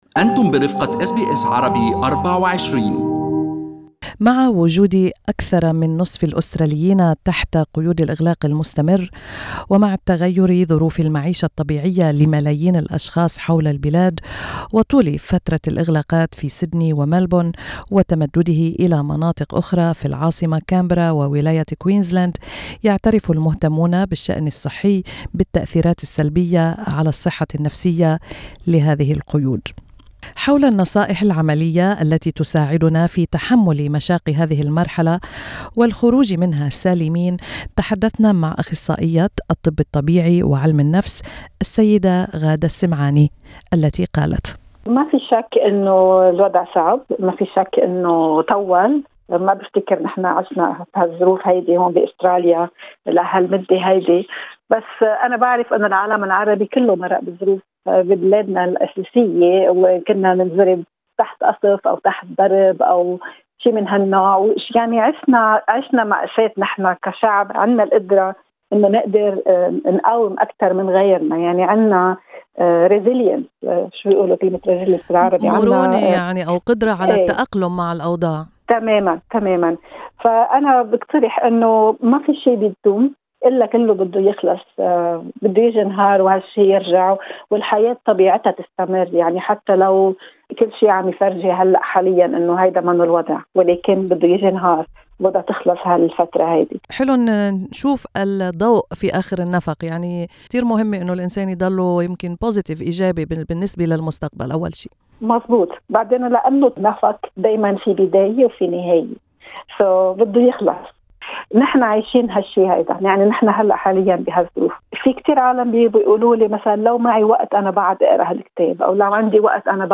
وفي لقاء مع SBS Arabic24